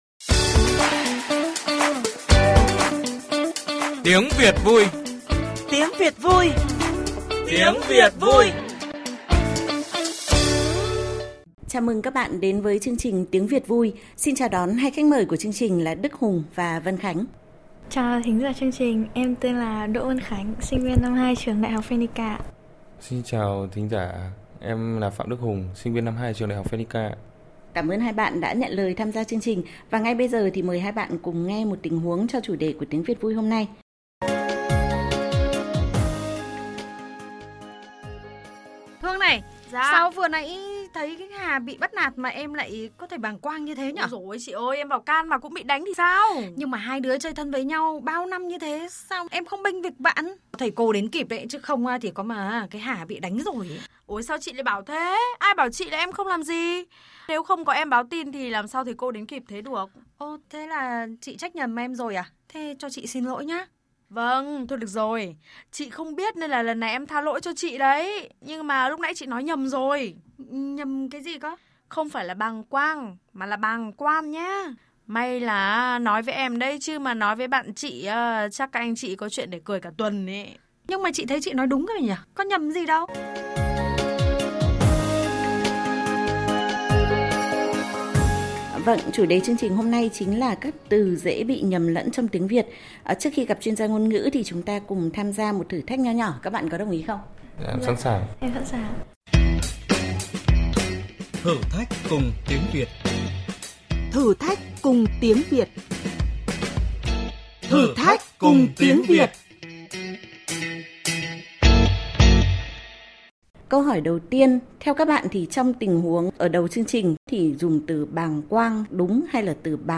[VOV2] – Trong Chương trình Tiếng Việt vui trên kênh VOV2, các bạn trẻ thử thách chọn từ đúng: bàng quan hay bàng quang, chín muồi hay chín mùi… Mời quý vị và các bạn cùng nghe cuộc trao đổi của phóng viên chương trình với Tiến sĩ